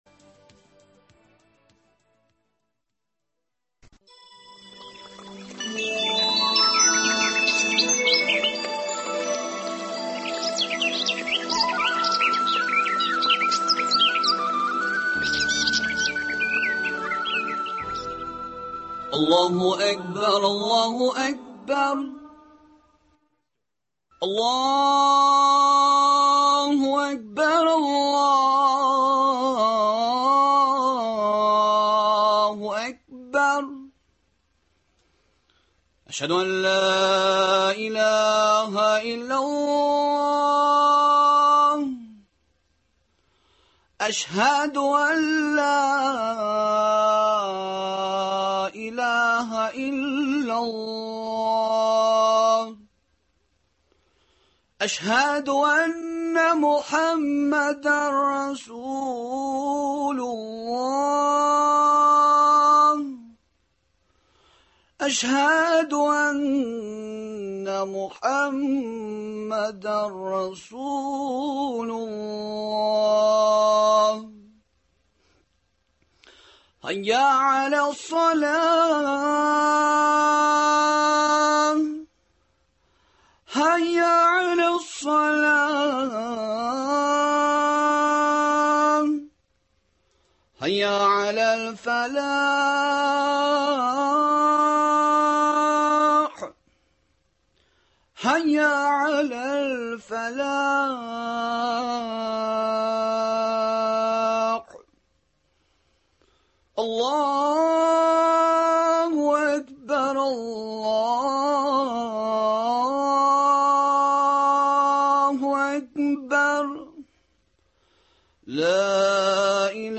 әңгәмәдән белә аласыз